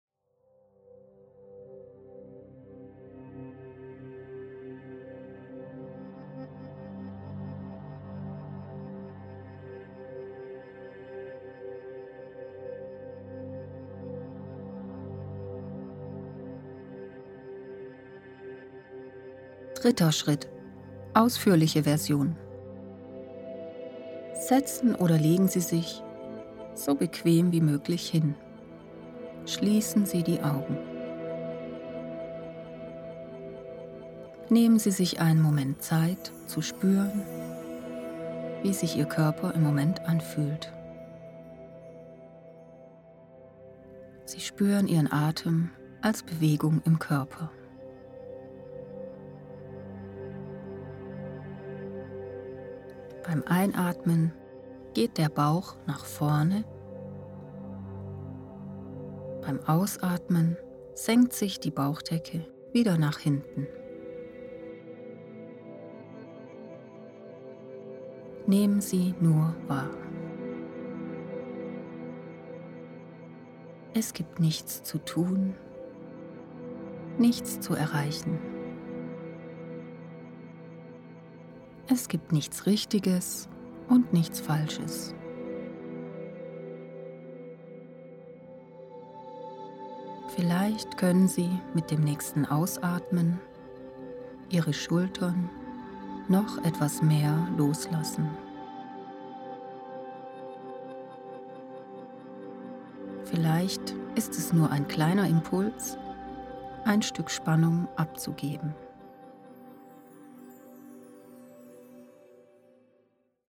Diese CD ist ein Übungsprogramm in der Entspannungsmethode Autogenes Training.